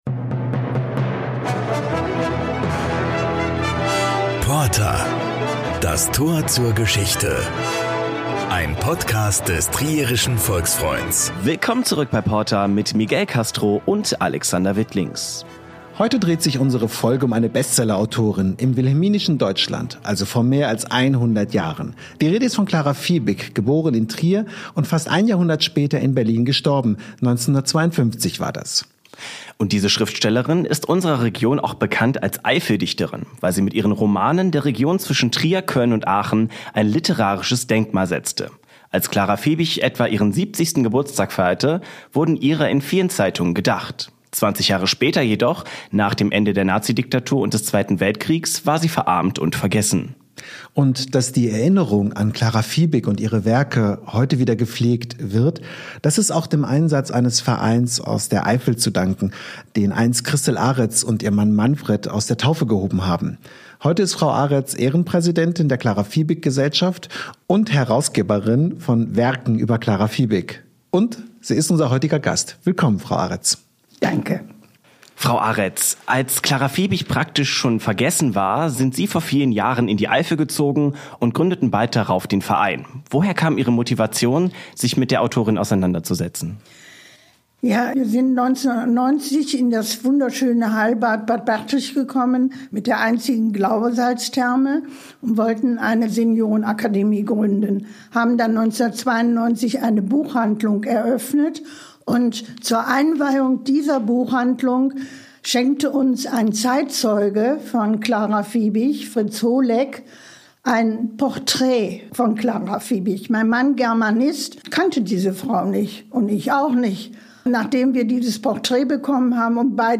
Wir unterhalten uns mit einer Expertin aus Trier über den Werdegang Clara Viebigs - und natürlich über ihre Romane und ihr Skandalbuch "Weiberdorf".